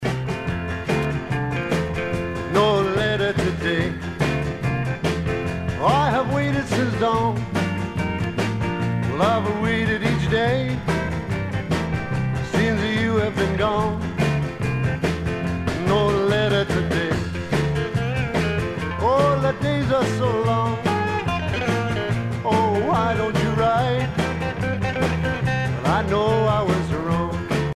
danse : rock
Pièce musicale éditée